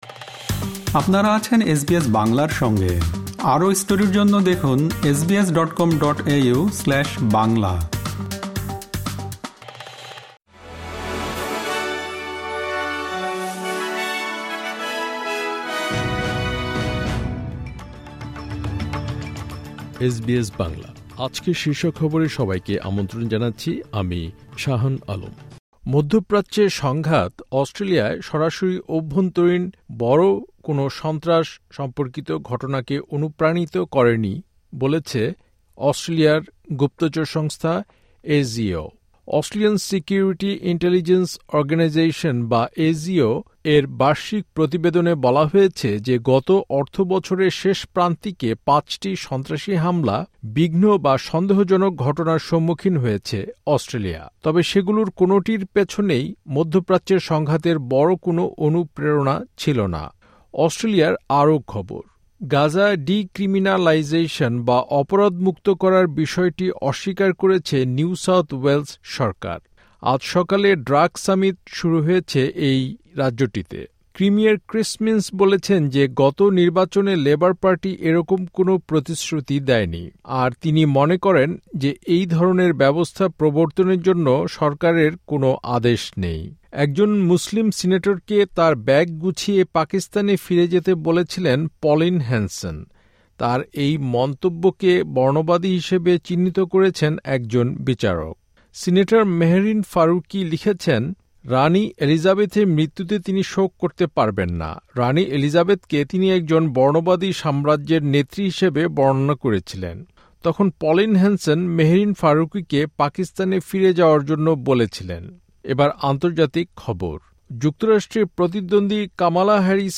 আজকের শীর্ষ খবর মধ্যপ্রাচ্যে সংঘাত অস্ট্রেলিয়ায় সরাসরি অভ্যন্তরীণ বড় কোনো সন্ত্রাস সম্পর্কিত ঘটনাকে অনুপ্রাণিত করে নি, বলেছে অস্ট্রেলিয়ার গুপ্তচর সংস্থা ASIO (এই-জি-ও)।